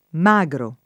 magro [